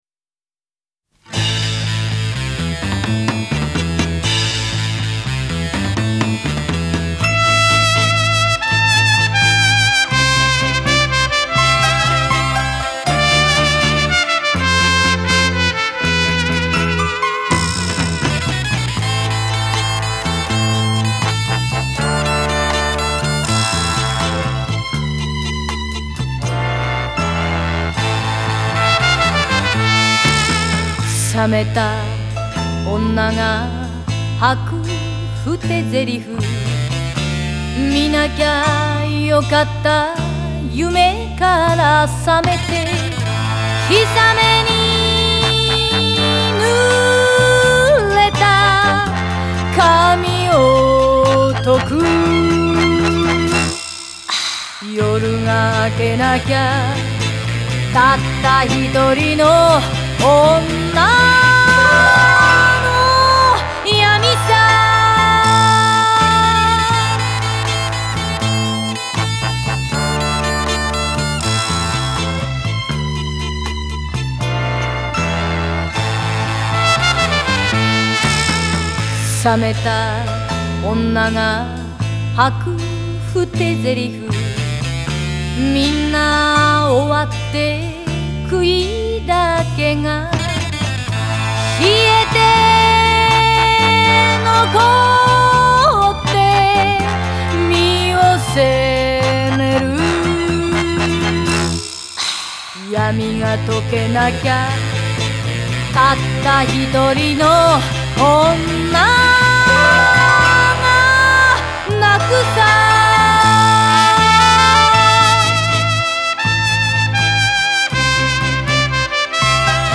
Жанр: Enka / J-pop